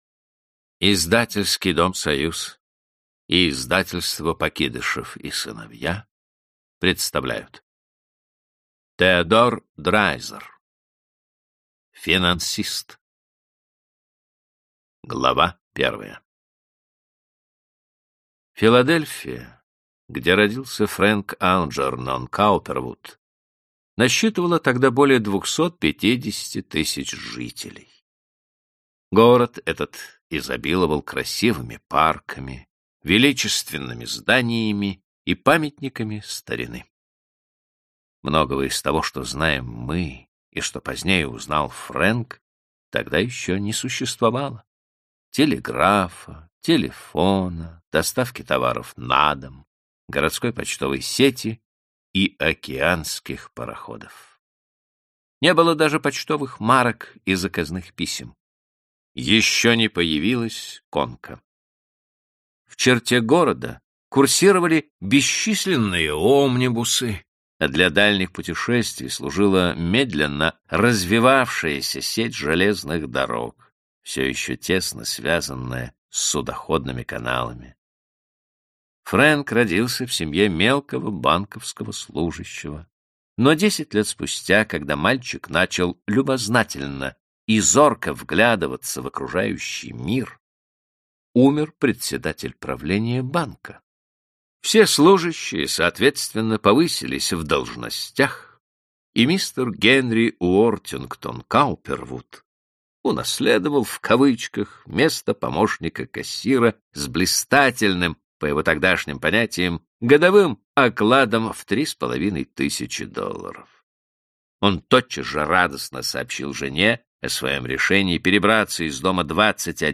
Аудиокнига Финансист - купить, скачать и слушать онлайн | КнигоПоиск